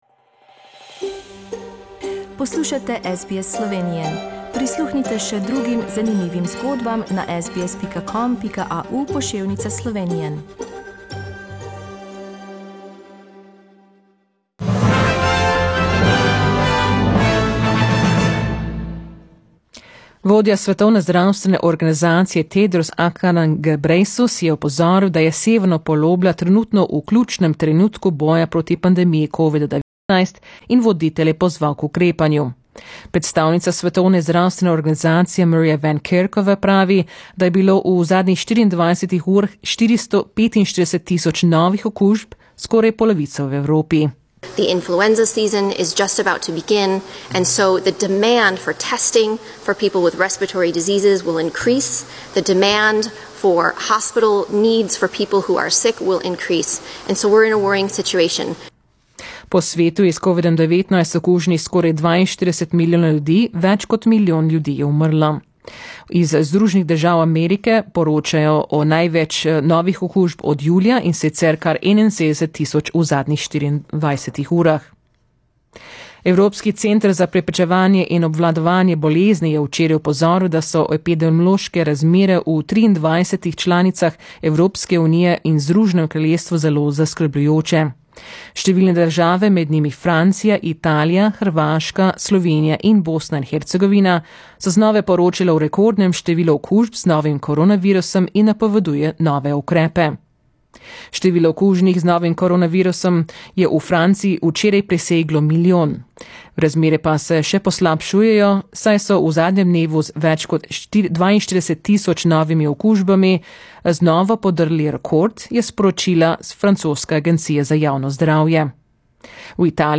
Poročila Radia SBS v slovenščini 24. oktobra